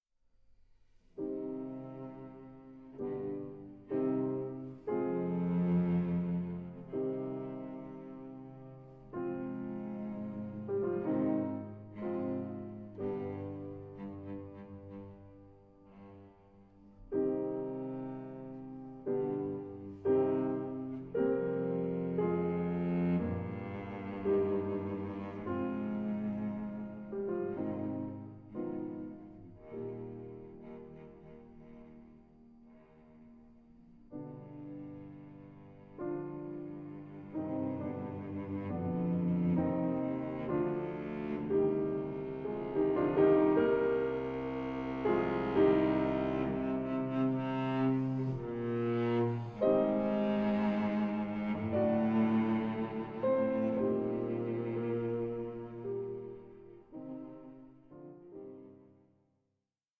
Adagio molto 7:49